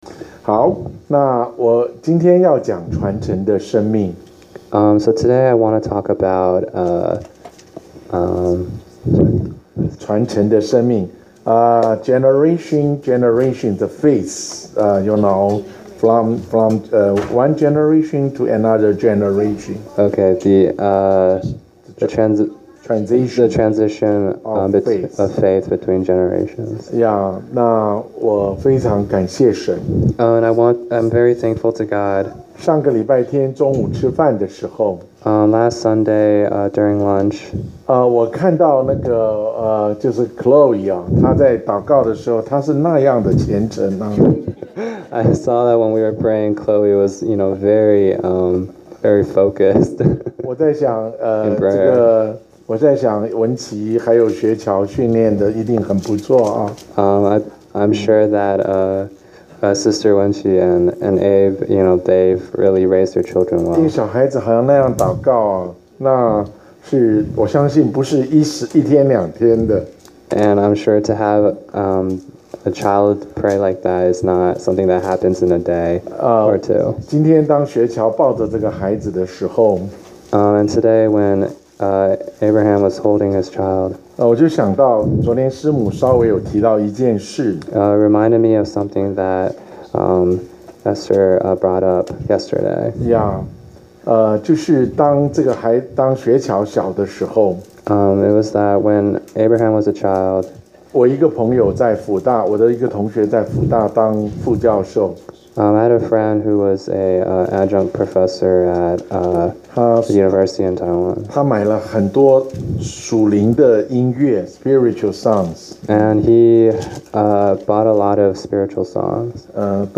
講道下載